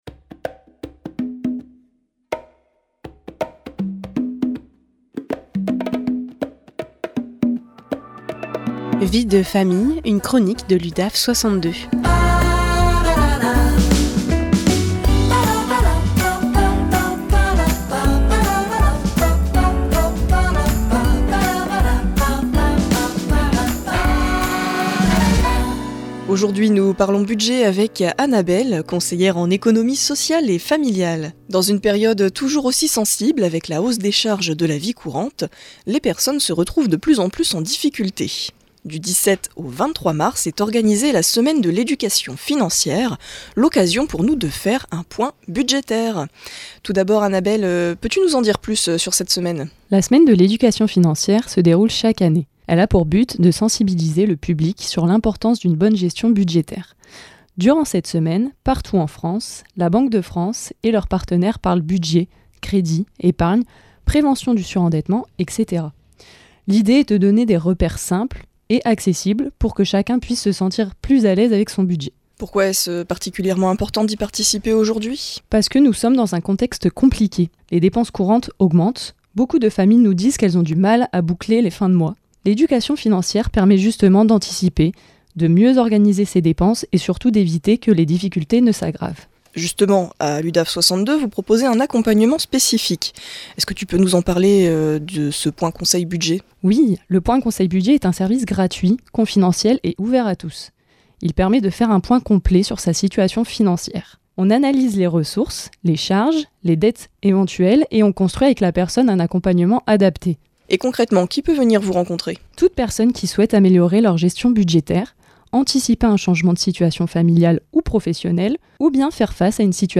Les professionnels de l’Udaf 62 interviennent au micro de PFM Radio à Arras, en proposant des chroniques sur divers sujets en lien avec leurs services respectifs.
Vie de Famille, une chronique de l’Udaf62 en live sur RADIO PFM 99.9